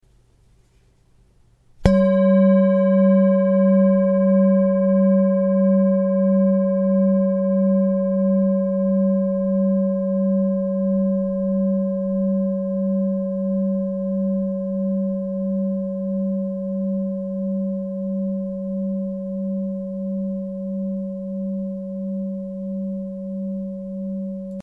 Tibetische Klangschale - ERDE SONNENTAG + IXION
Gewicht: 894 g
Grundton: 193,73 Hz
1. Oberton: 554,55 Hz
KM72D-894g Klangschale.mp3